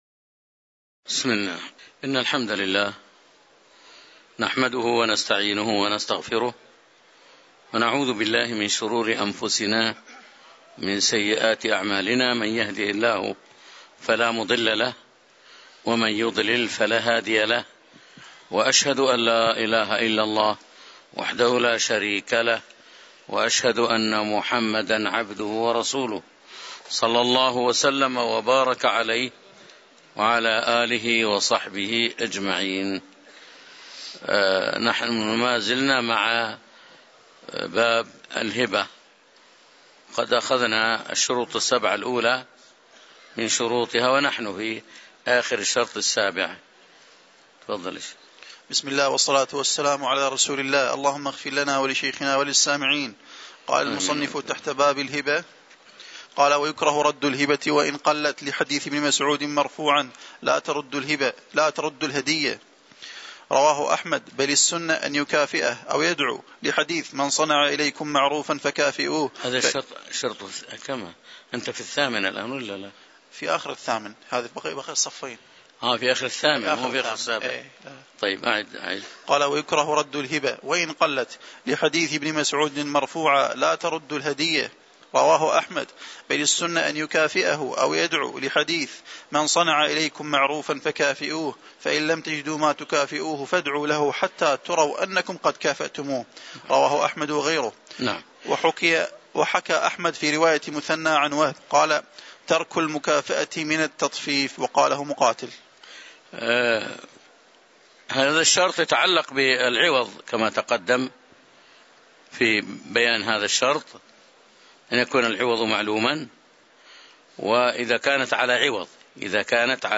تاريخ النشر ٣ جمادى الآخرة ١٤٤٤ هـ المكان: المسجد النبوي الشيخ